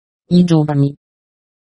I made the soundfiles with the text-to-speech program at: